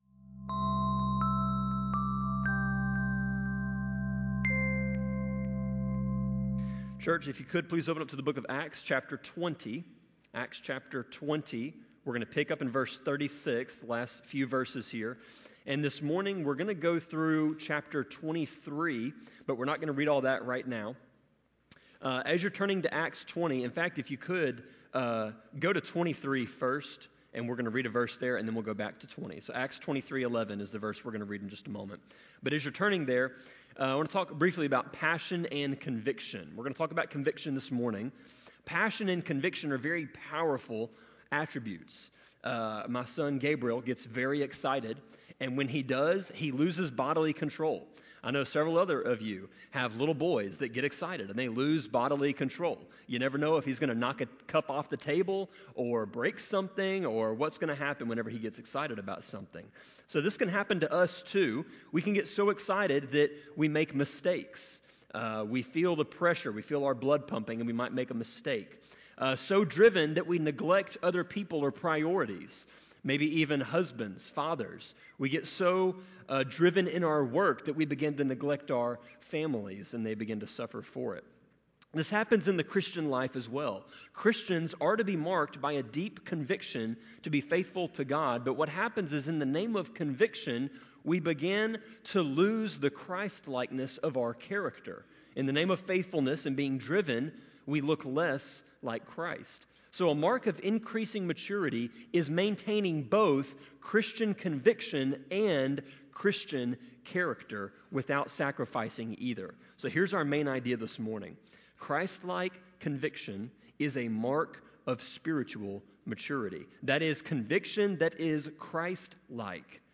Sermon-24.9.22-CD.mp3